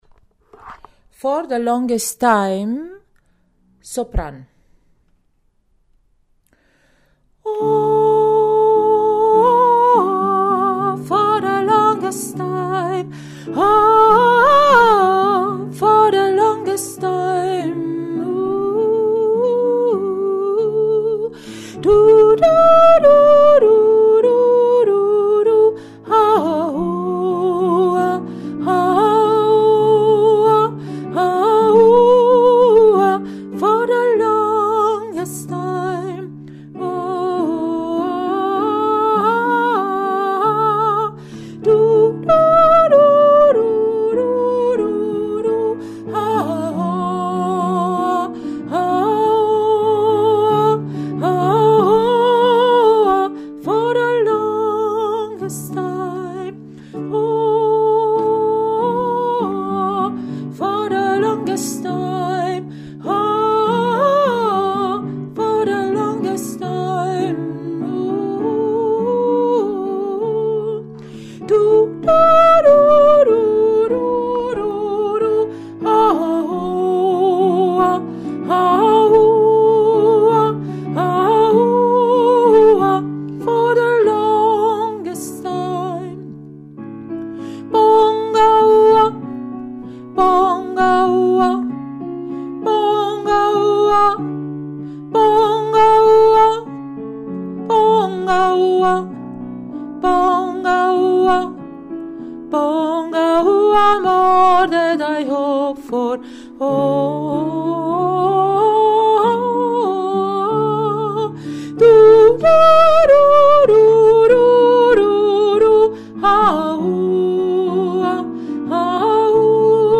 For the longest time – Sopran